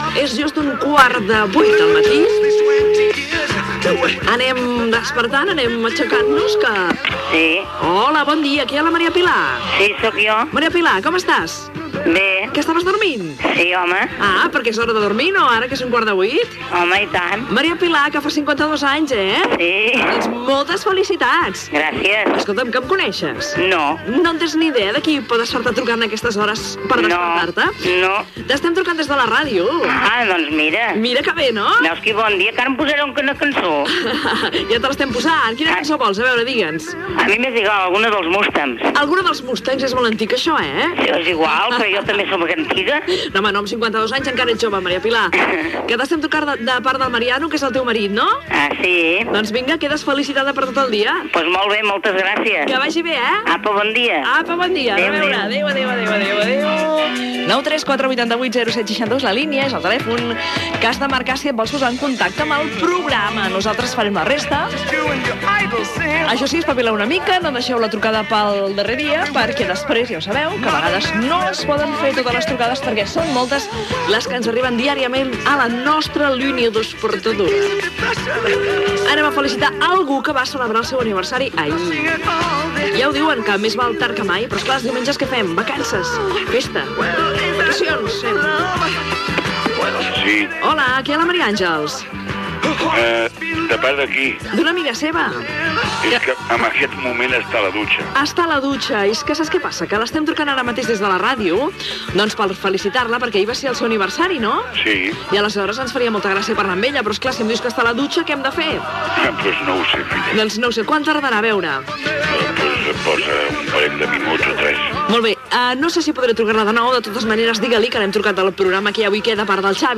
Hora, felicitacions telefòniques als oients i tema musical
Entreteniment
FM